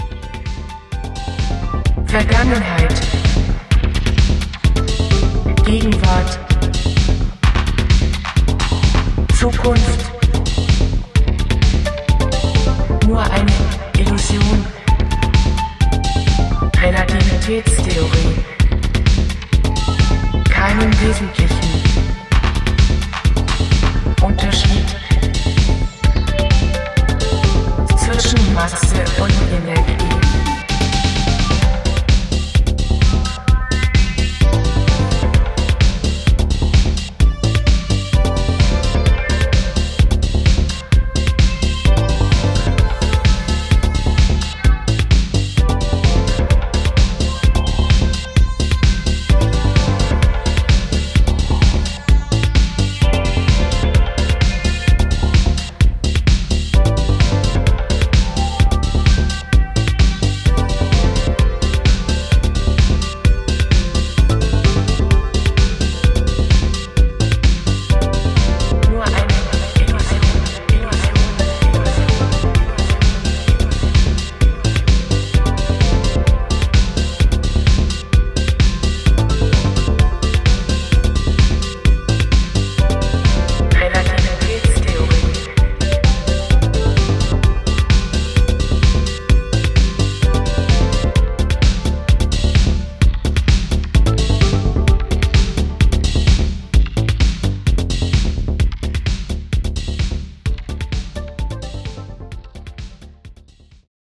重いディスコビート上をトランシーなメロディーが舞う